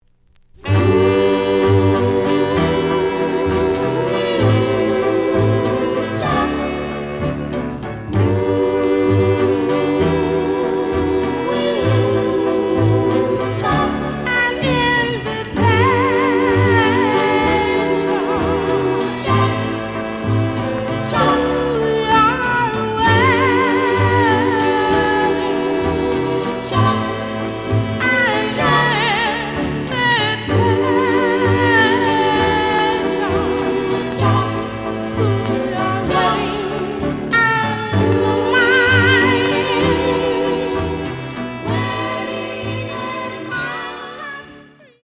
The audio sample is recorded from the actual item.
●Genre: Rhythm And Blues / Rock 'n' Roll
Some click noise on A side due to scratches.